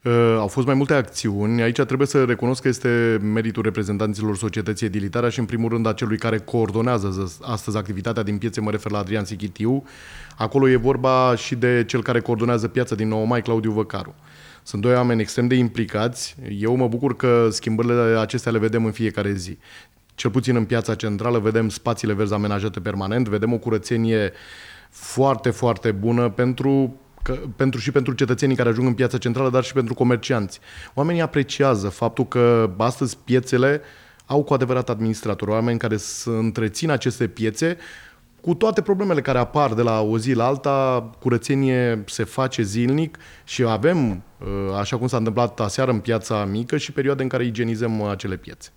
Marcel Romanescu, Primar Târgu Jiu